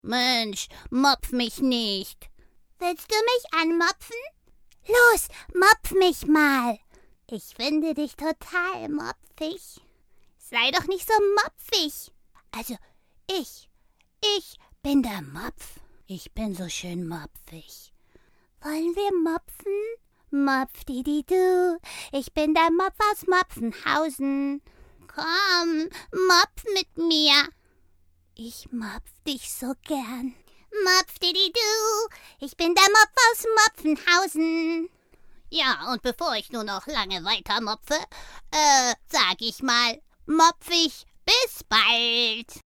Gefällt Euch diese Mopf-Stimme?